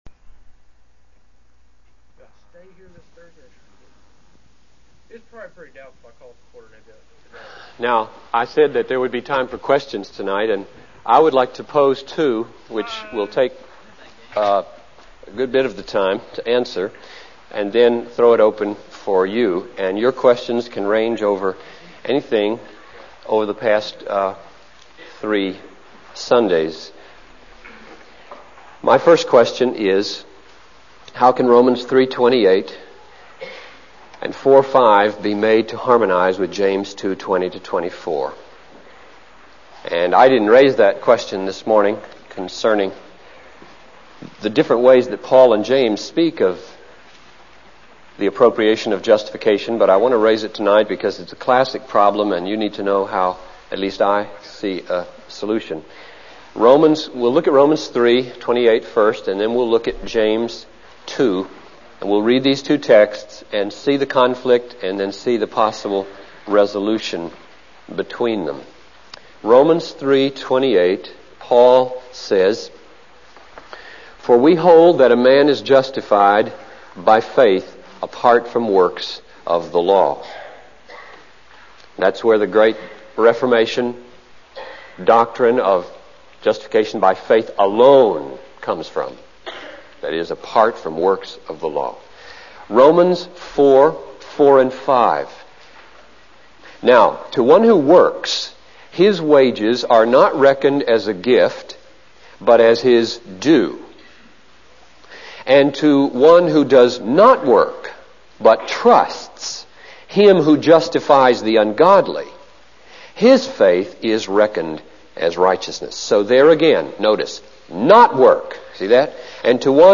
In this sermon, Dr. Piper discusses the concept of rewards in heaven based on the degree of holiness achieved on earth.